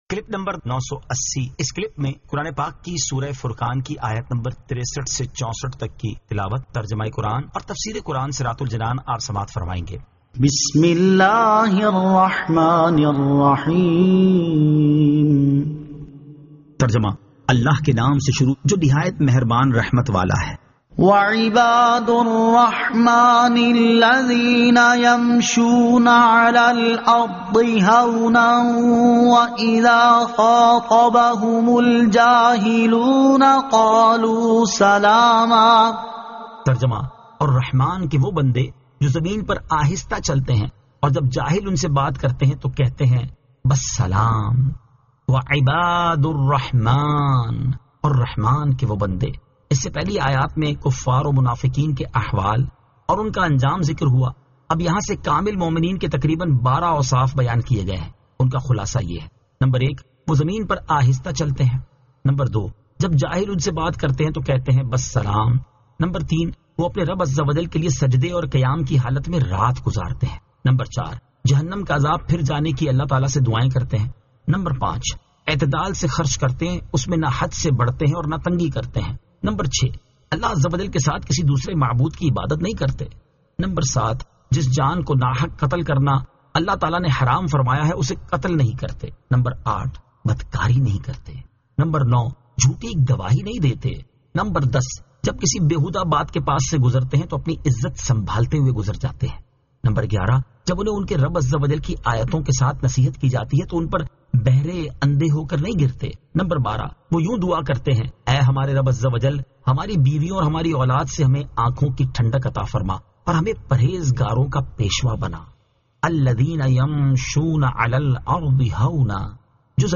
Surah Al-Furqan 63 To 64 Tilawat , Tarjama , Tafseer